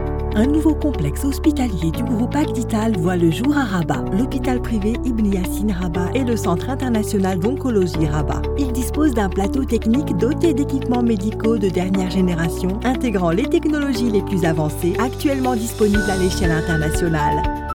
Corporate & Industrial Voice Overs
Yng Adult (18-29) | Adult (30-50)